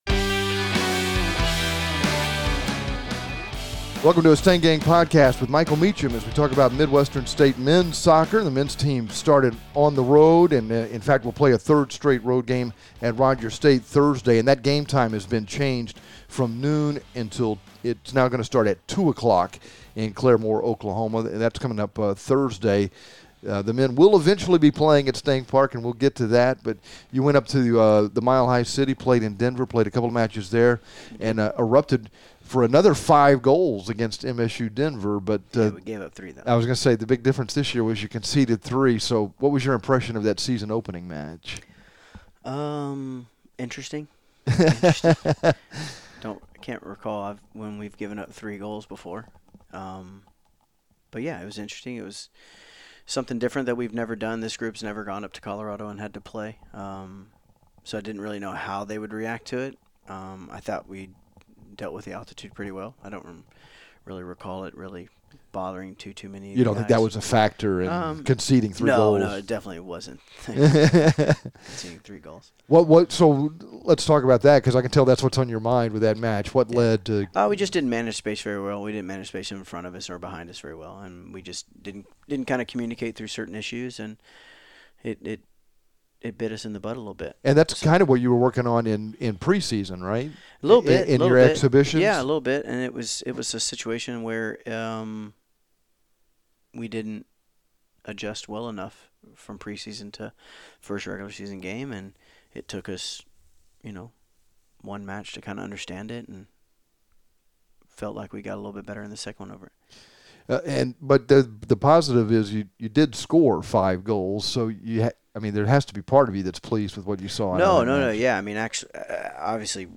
STANG GANG MEN'S SOCCER PODCAST
This week's Stang Gang Podcast features complete coverage of Midwestern State Men's Soccer and an interview